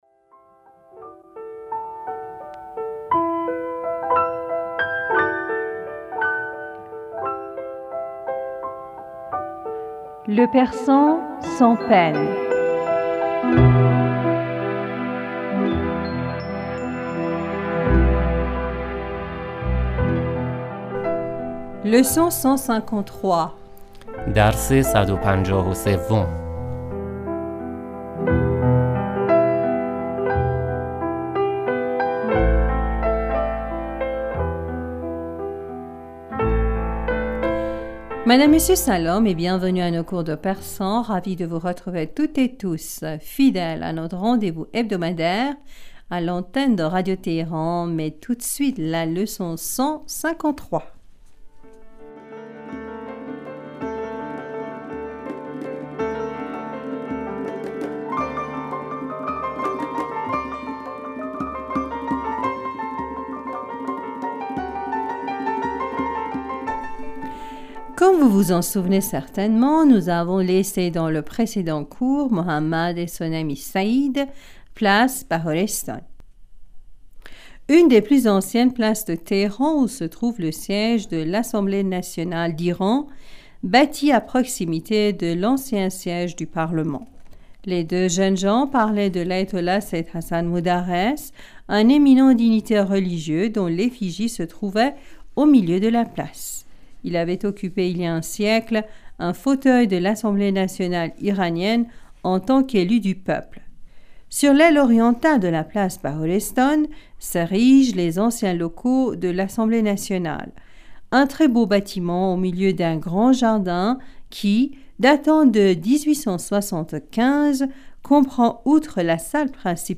Madame, Monsieur, Salam et bienvenus à nos cours de persan.